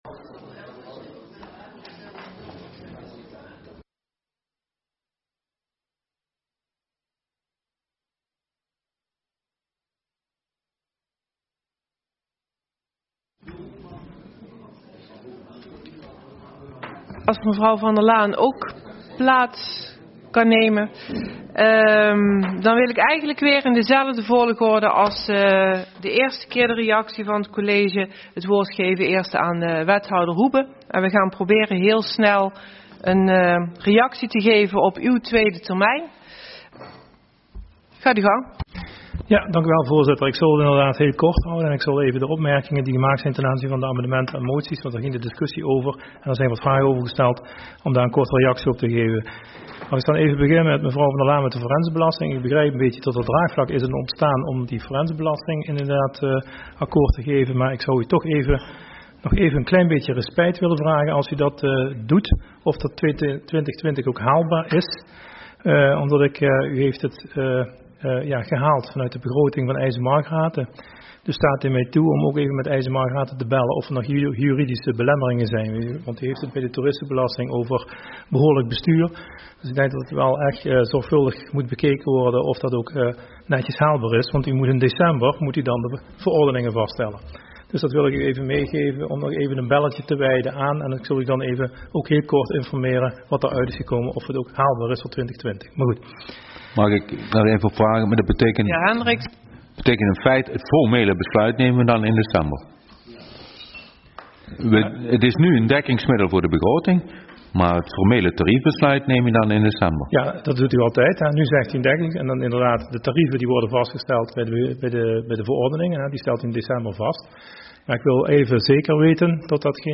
Agenda GulpenWittem - Raadsvergadering Begroting 2020 donderdag 7 november 2019 16:00 - 00:00 - iBabs Publieksportaal